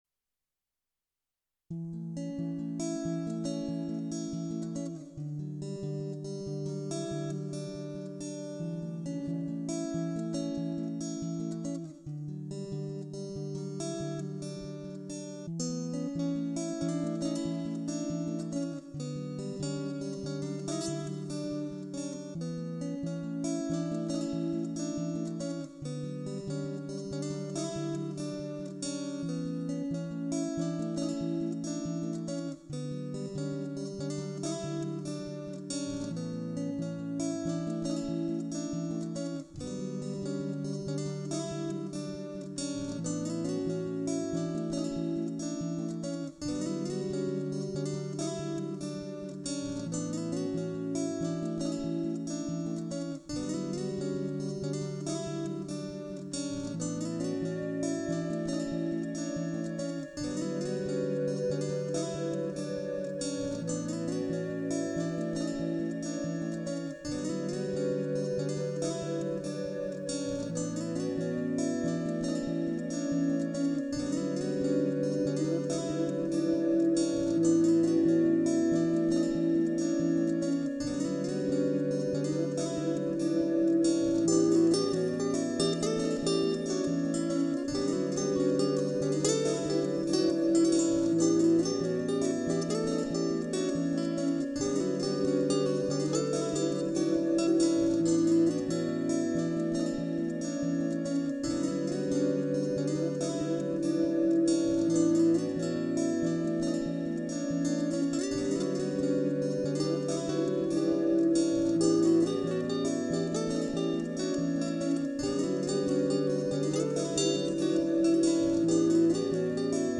Dit stuk is geschreven en gespeeld door alleen mijzelf, waarbij gebruik gemaakt wordt van een enkele gitaar en een looper/multi-effectpedaal. Het is een kalm stuk, met laagjes die constant worden toegevoegd om uiteindelijk ruimte te maken voor de melodie die steeds maar weer terugkomt.